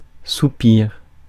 Ääntäminen
Ääntäminen : IPA: /su.piʁ/ Haettu sana löytyi näillä lähdekielillä: ranska Käännös Konteksti Ääninäyte Substantiivit 1. sigh US 2. crotchet rest musiikki 3. quarter rest musiikki Suku: m .